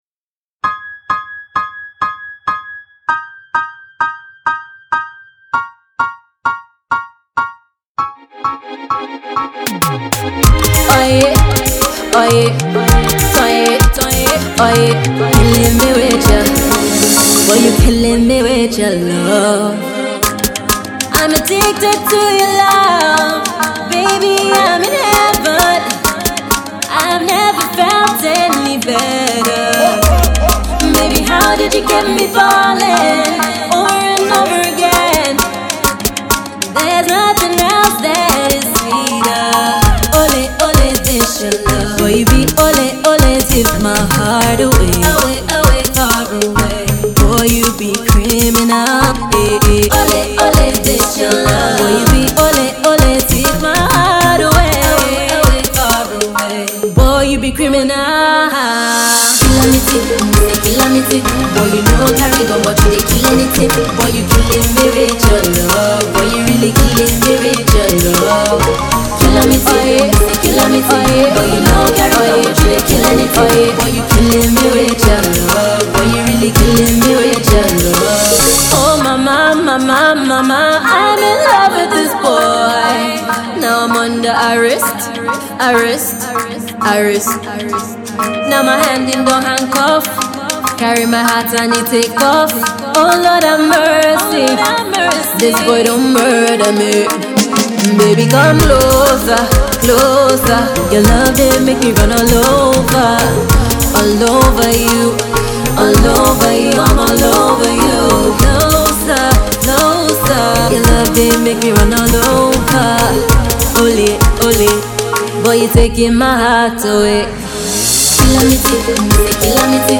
Hypnotic. Alluring. Dancehall.